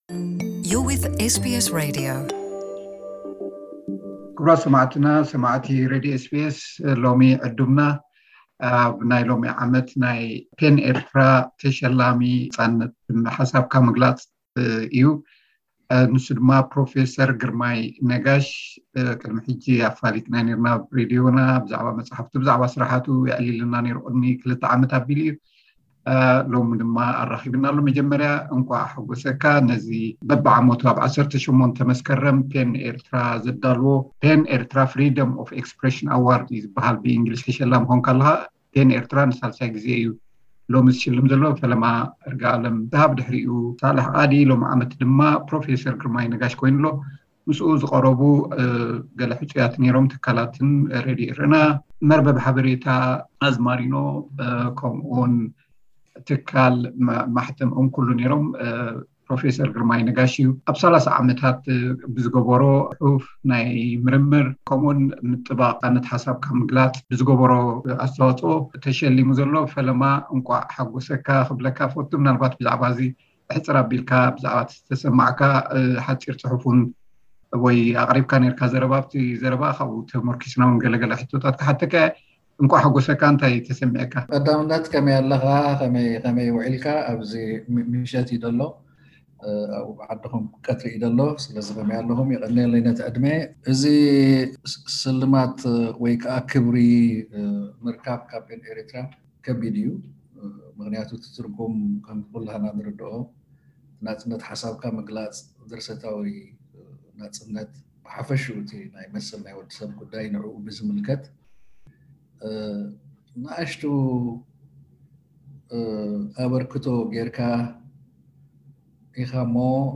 ምስኡ ዝገበርናዮ ጻንሒት ኣብዚ ቀሪቡ ኣሎ።